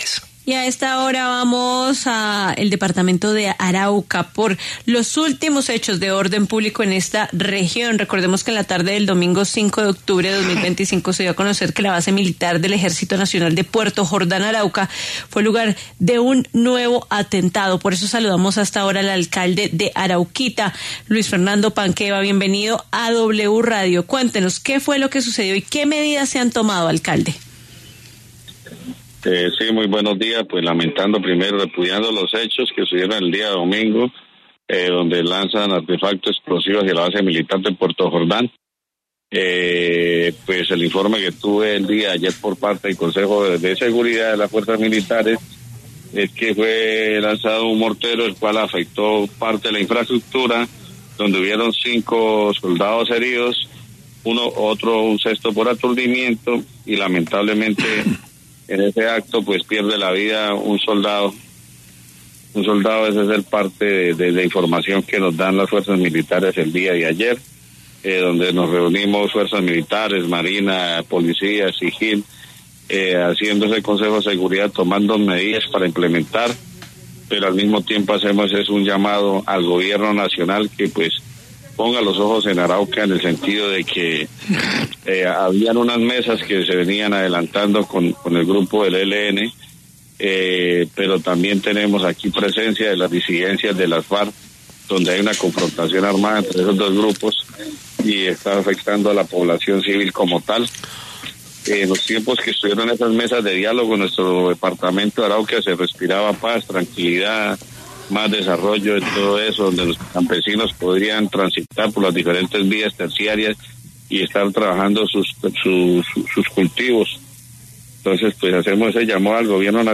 Luis Fernando Panqueva, alcalde de Arauquita (Arauca), habló en La W sobre el atentado en Puerto Jordan.